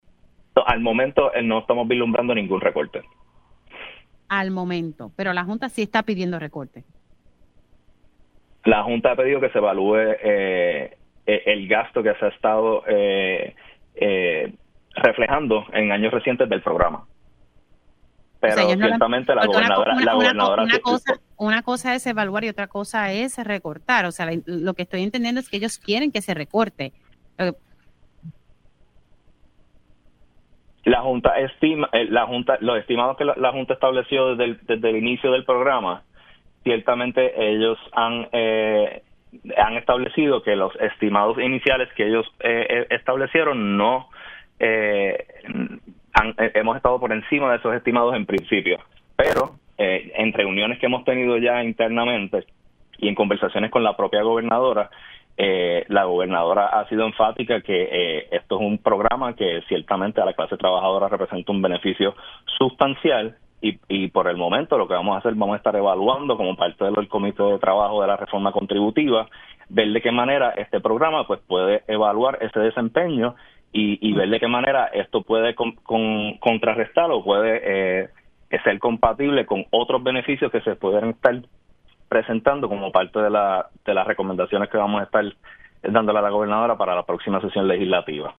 El secretario del Departamento de Hacienda, licenciado Ángel Pantoja, reiteró en Pega’os en la Mañana que no vislumbra que el beneficio del Crédito por Trabajo sea eliminado, luego de que la Junta de Control Fiscal (JCF) anticipara su revisión.